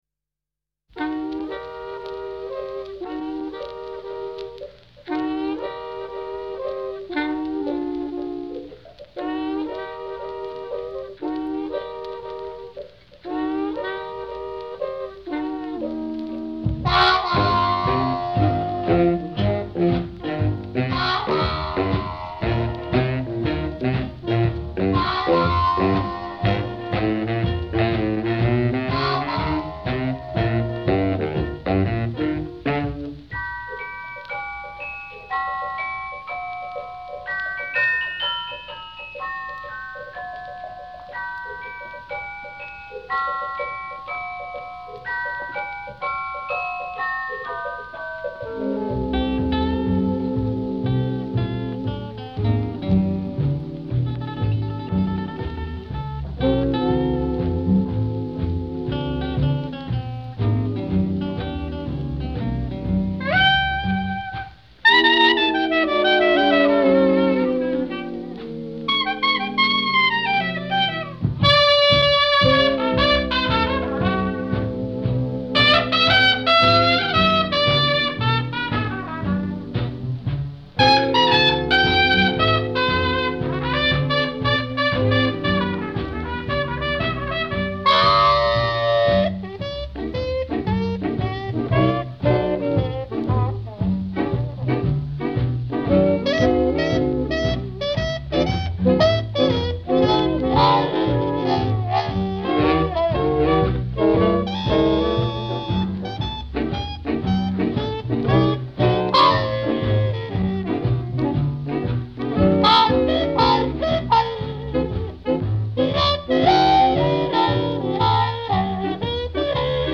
Big Band
• SWING (JAZZ)
• Trumpet
• Clarinet
• Guitar
• Bass
• Drums